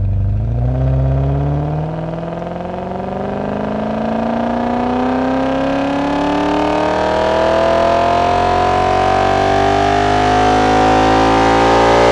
v8_11_accel.wav